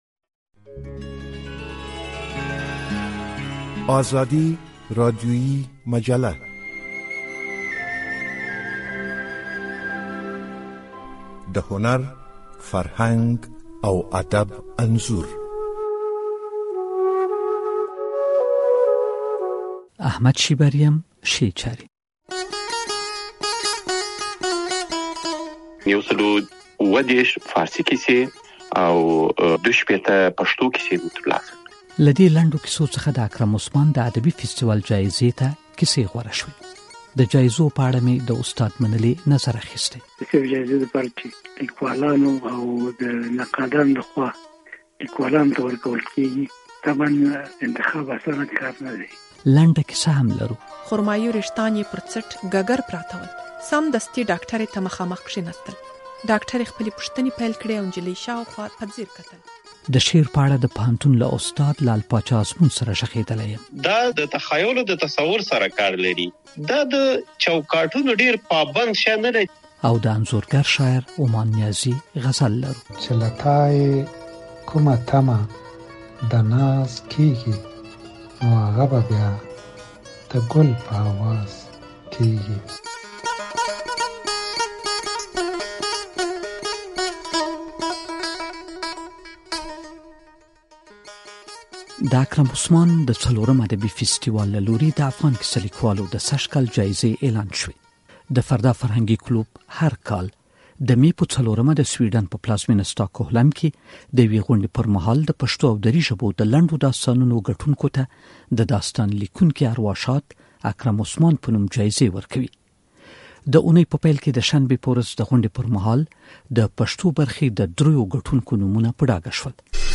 راډیویي مجله